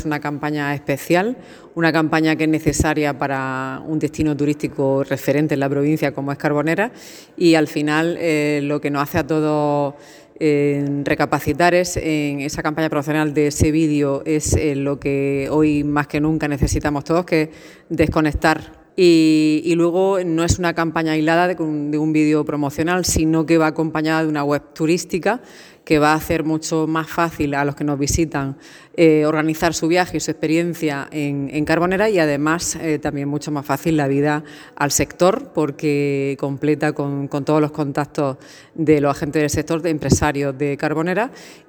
Declaraciones-diputada-Turismo.mp3